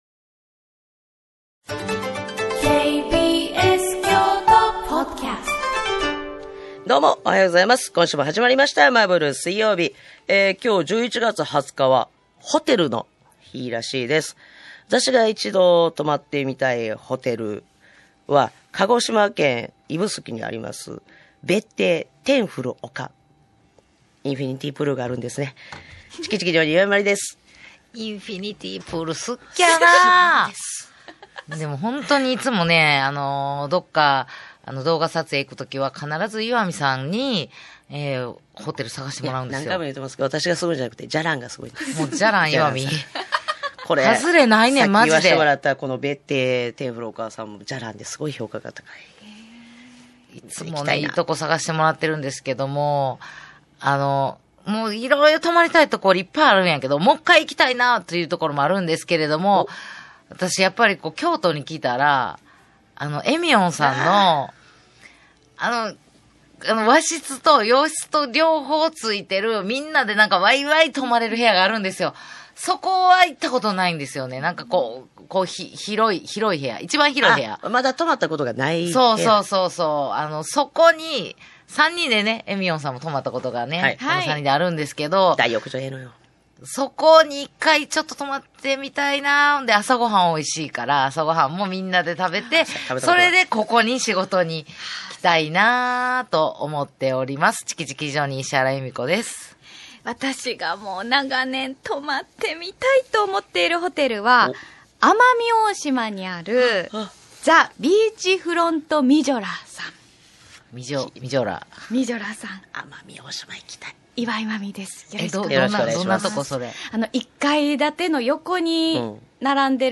【KBS京都ラジオ 毎週水曜日 10:00〜14:00 オンエア】アラサー・アラフォーの独身女子たちが送るバラエティラジオ。グルメや旅行の話題から、今すぐ言いたいちょっとした雑学、みんな大好き噂話まで気になる話題が満載。